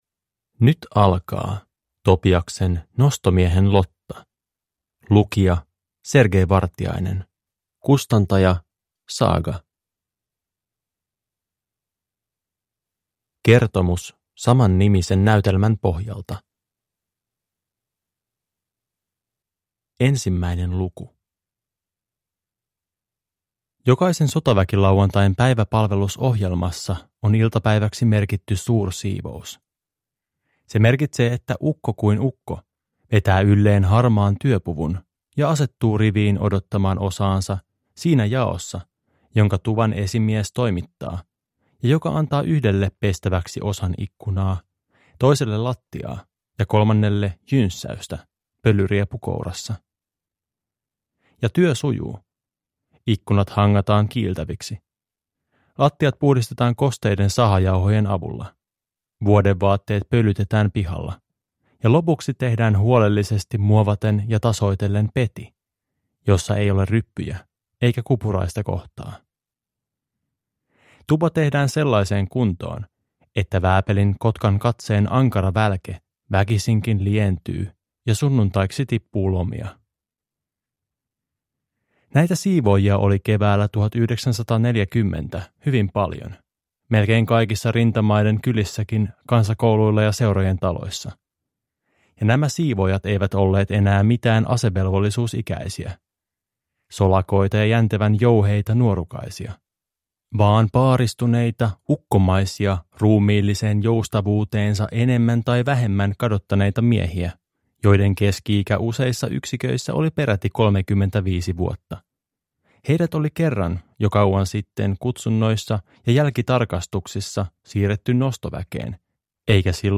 Nostomiehen lotta (ljudbok) av Topias